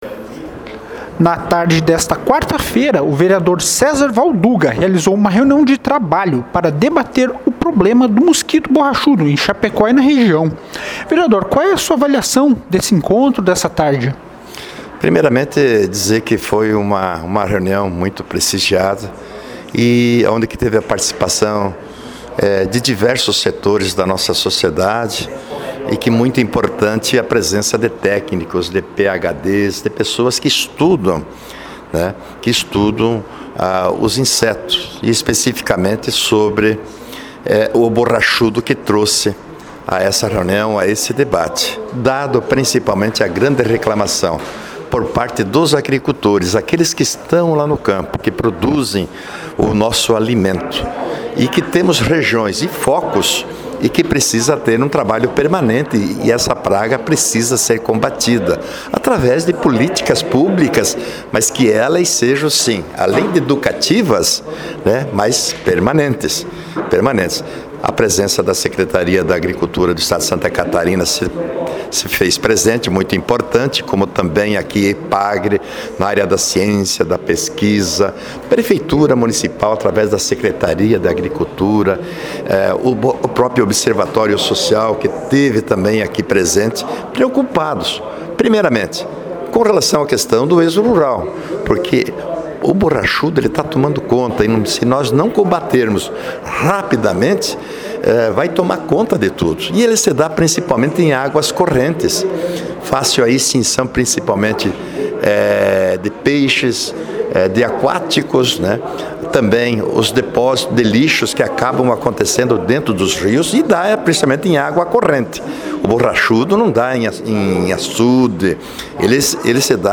Áudio do vereador Valduga sobre a reunião de trabalho: